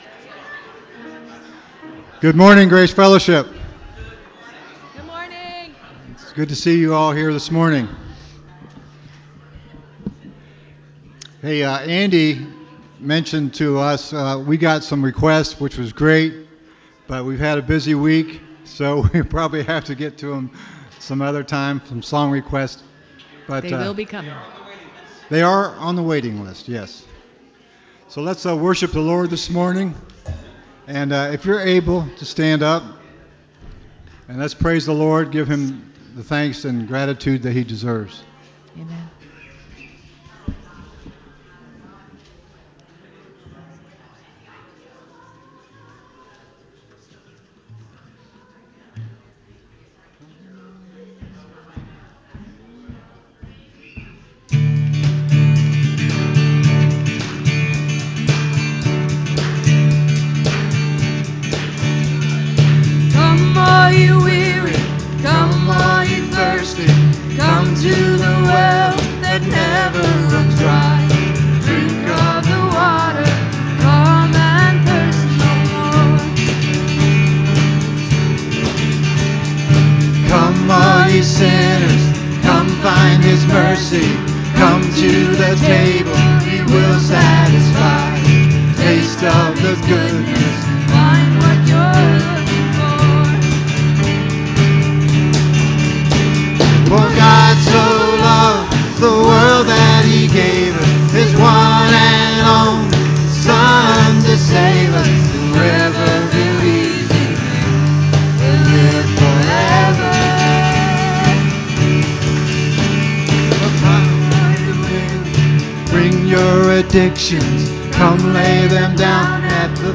This week is Celebration Sunday! Also known as All Worship Sunday, it is a time to sing and dance a little more, pray, and tell others what God is doing in your life.